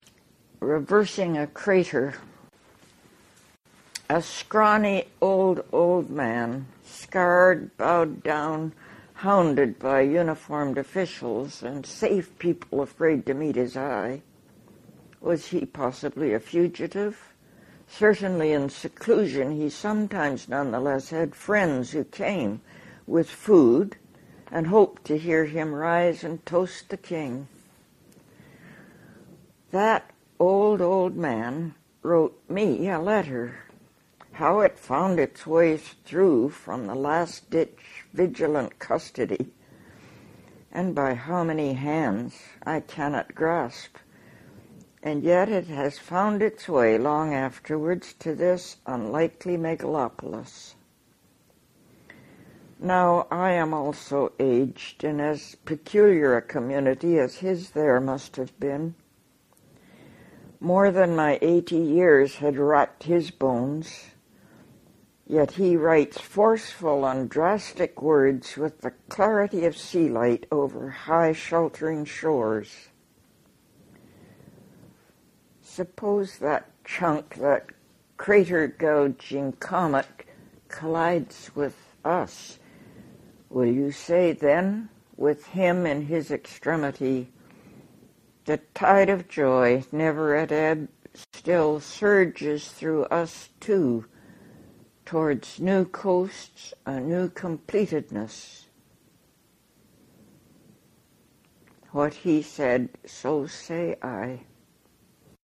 Margaret Avison reads Reversing a Crater from Concrete and Wild Carrot